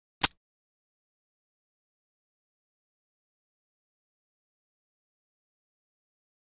Hand Cuffs; Hand Cuffs Clicks.